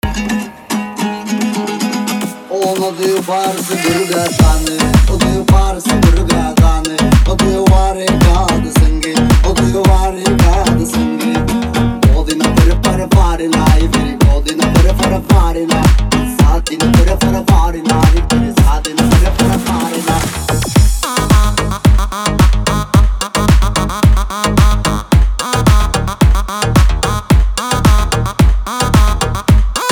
Жанр: Электроника / Русские